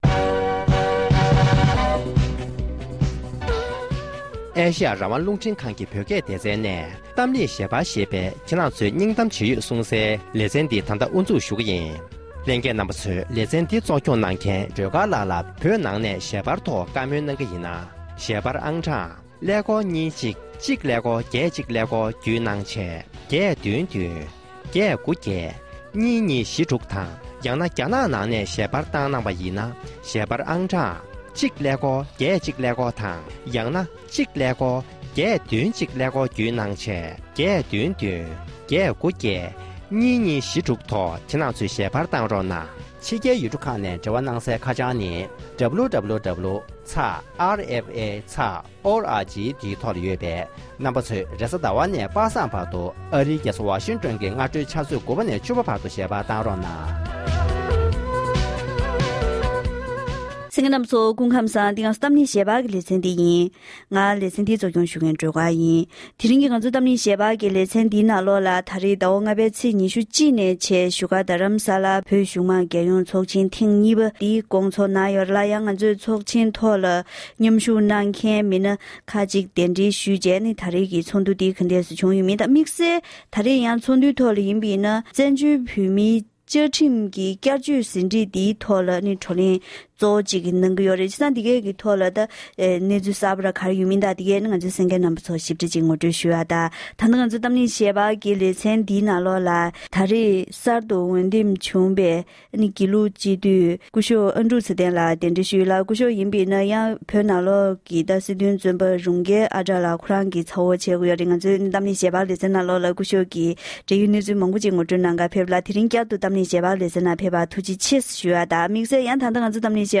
ཚོགས་ཆེན་ཐོག་མཉམ་ཞུགས་གནང་མཁན་ཁག་ཅིག་གི་ལྷན་གླེང་བ།